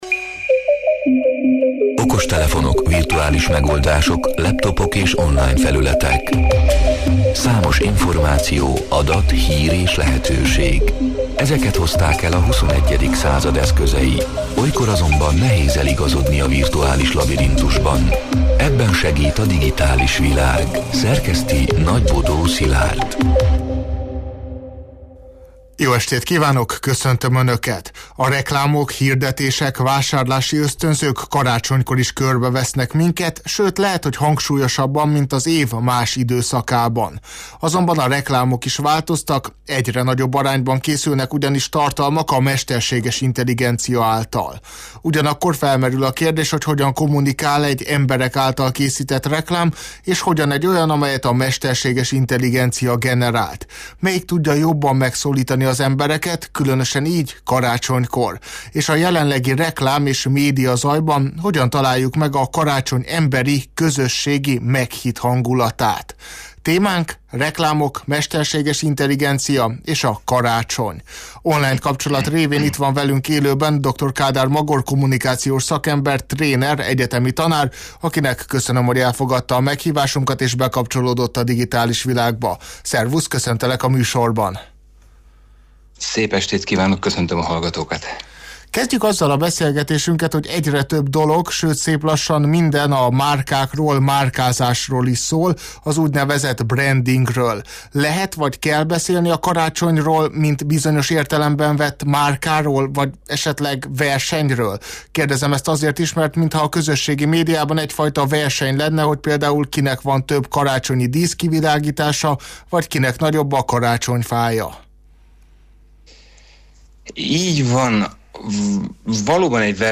A Marosvásárhelyi Rádió Digitális Világ (elhangzott: 2025. december 16-án, kedden este nyolc órától élőben) c. műsorának hanganyaga: